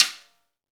Index of /90_sSampleCDs/Club-50 - Foundations Roland/KIT_xExt.Snare 4/KIT_xExt.Snr 4dS
SNR XEXTS0OL.wav